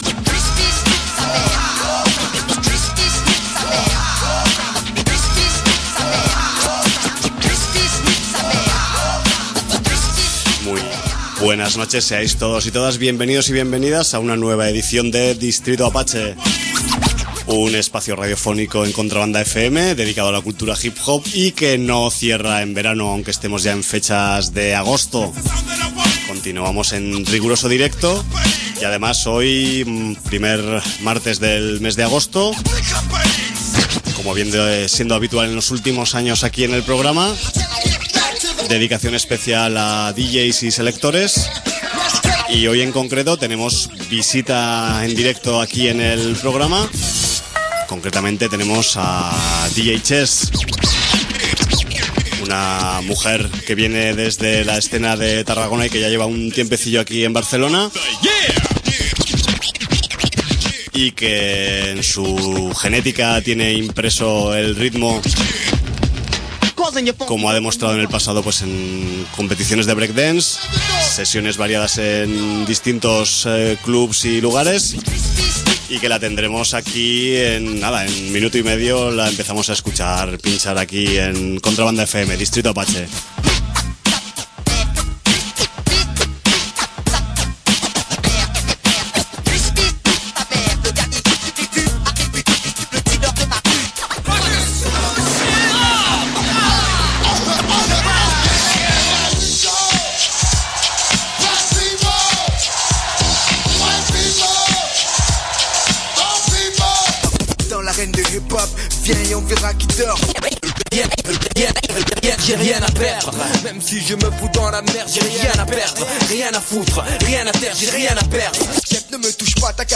nada mejor que una sesión en directo